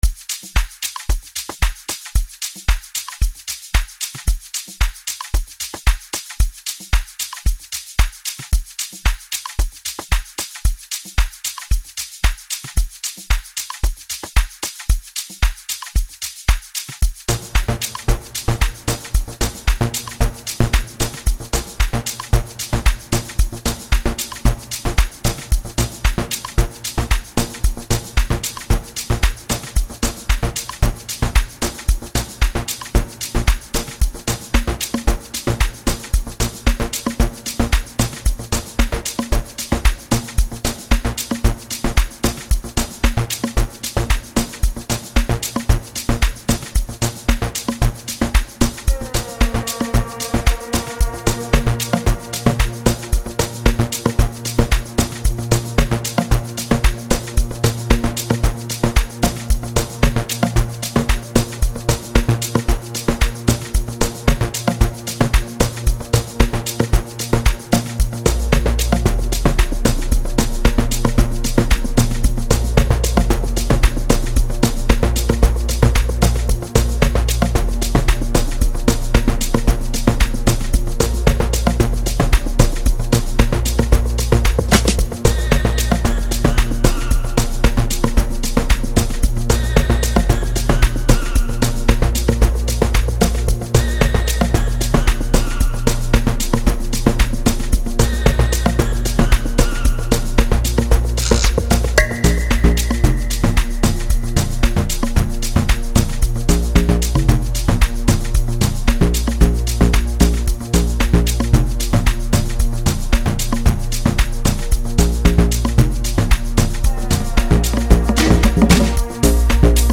3 months ago Afrobeat